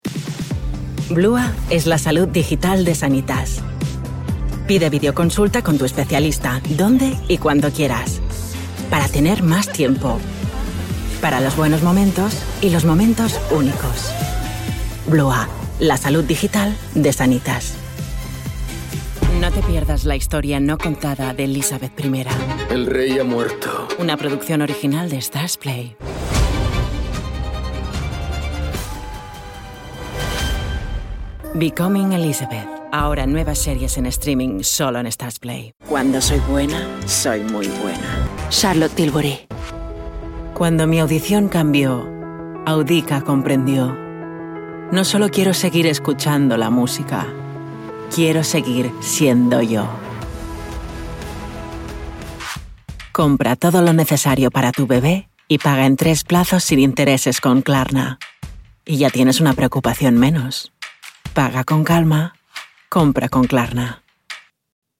Home Studio AKG C214 mic
Soundproofed booth